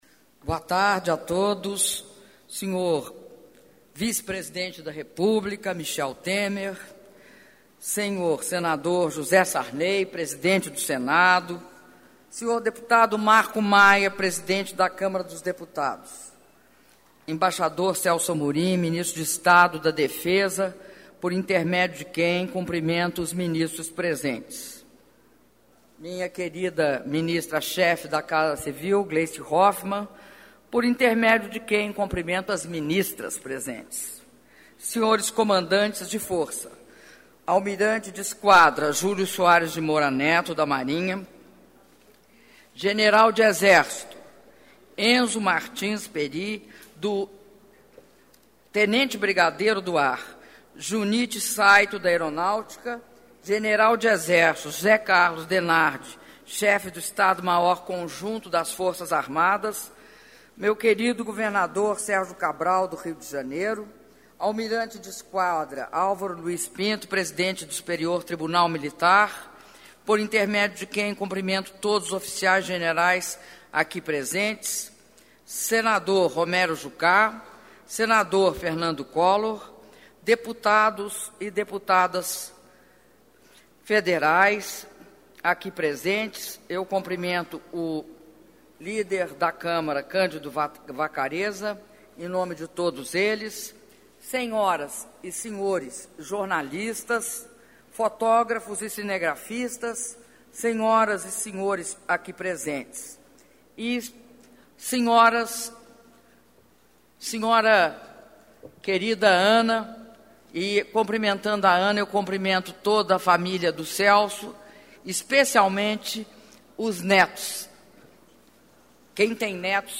Discurso da Presidenta da República, Dilma Rousseff, durante cerimônia de posse do ministro da Defesa, Celso Amorim - Brasília/DF
Palácio do Planalto, 08 de agosto de 2011